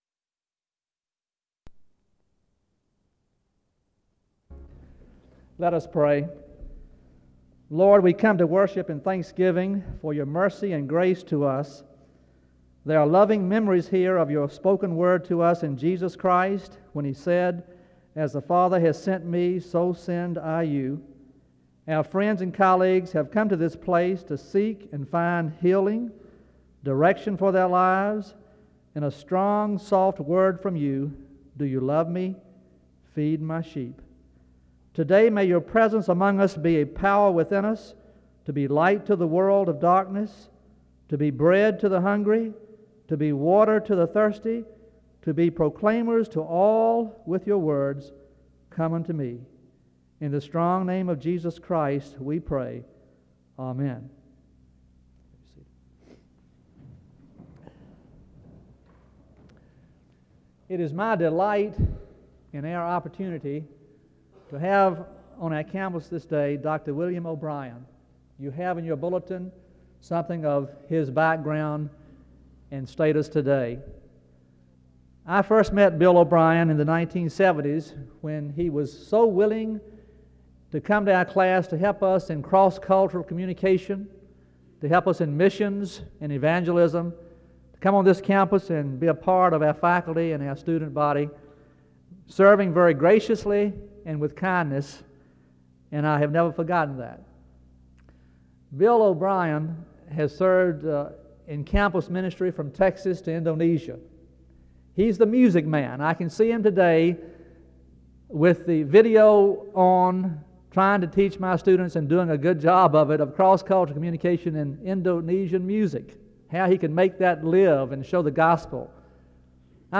The service opens with prayer from 0:00-0:53. An introduction to the speaker is given from 1:01-2:50.